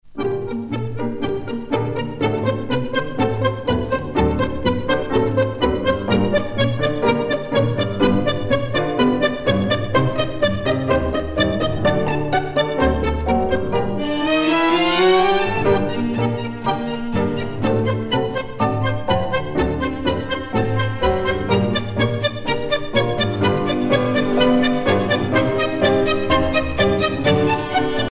danse : tango
Pièce musicale éditée